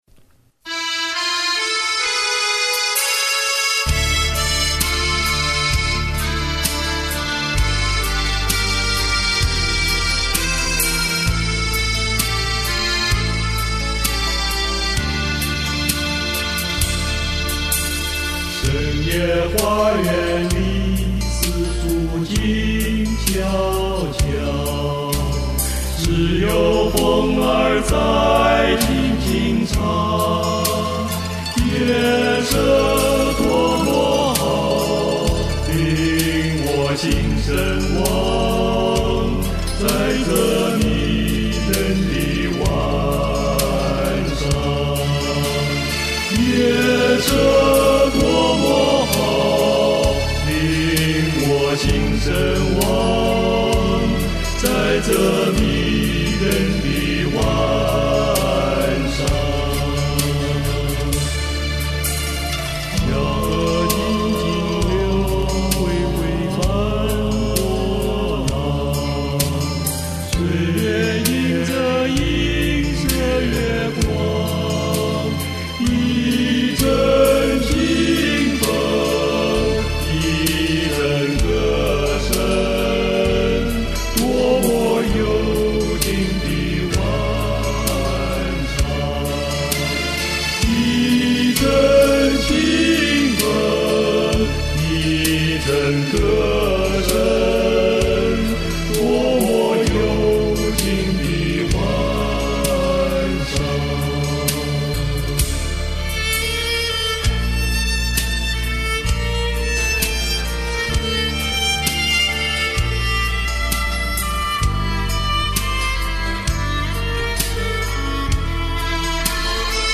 自男声四重唱